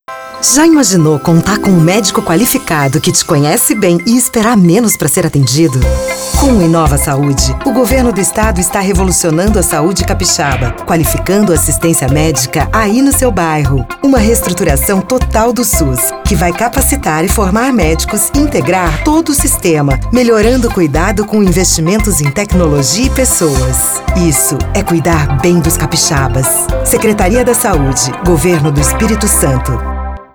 Feminino
Voz Padrão - Grave 00:30